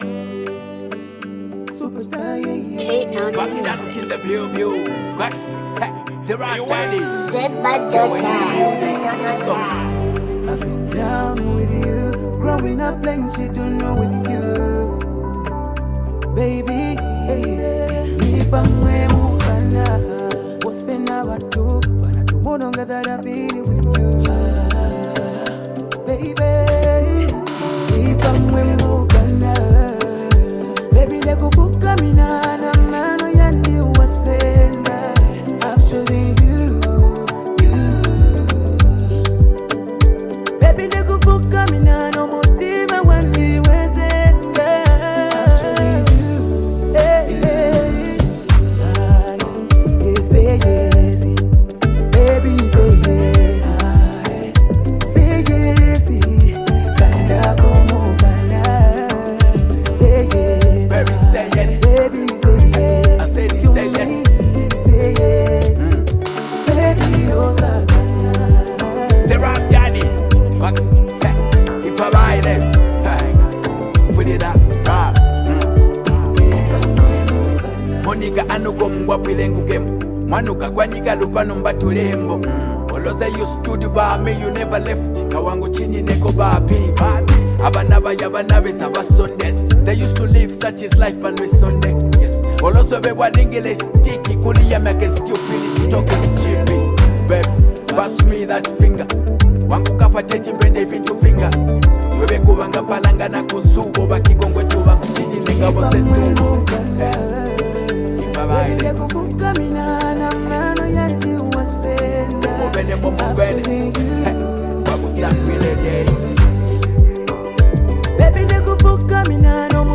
Category: Zambian Music